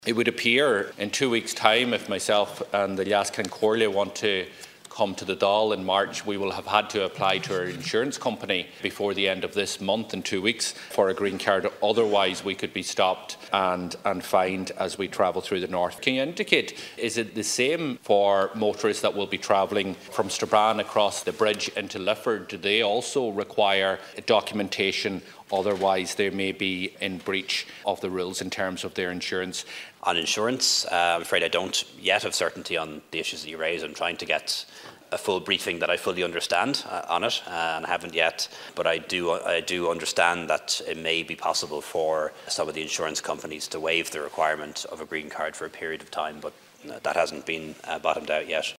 The Taoiseach has told the Dail that he’s not had any clear briefing about the implications of a no-deal Brexit for car insurance.